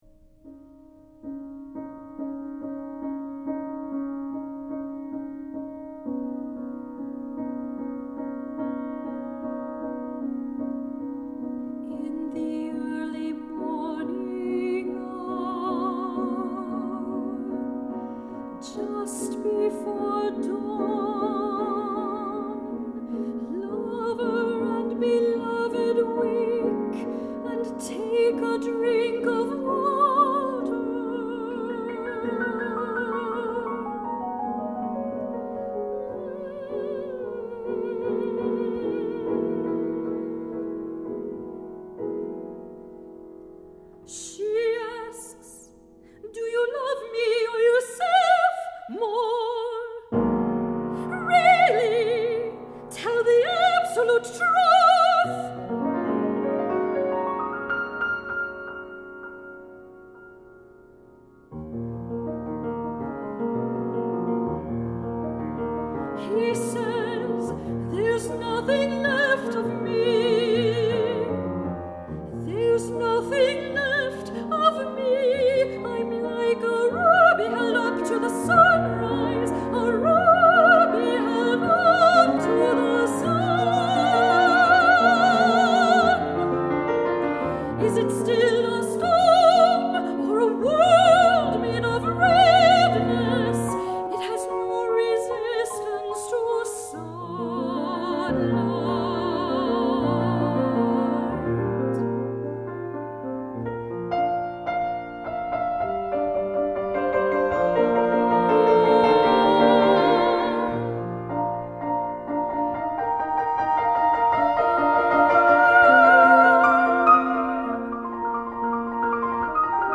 for High Voice and Piano (2002)
" is a passionate song.